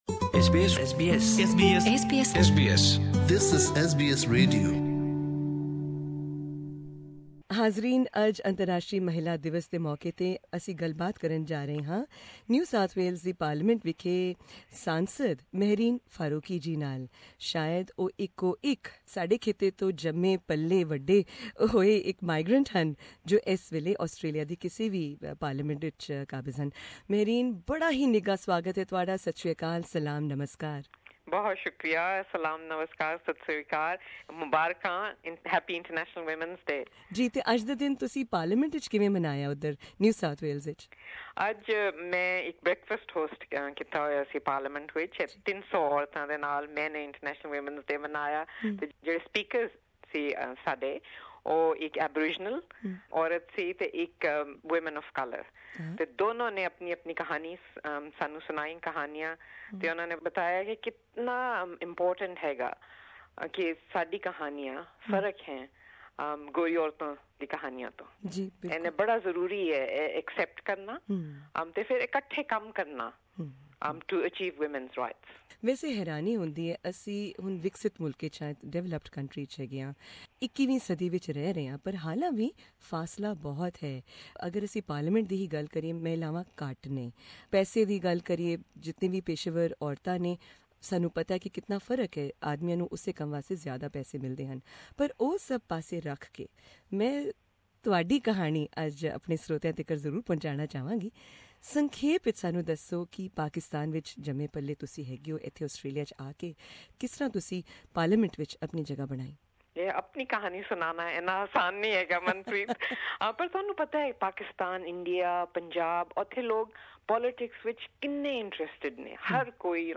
Member of the Upper House in NSW parliament Mehreen Faruqi spoke about the challenges she's faced, and how she's overcome them... as a woman of colour, a migrant and a Muslim in Australia.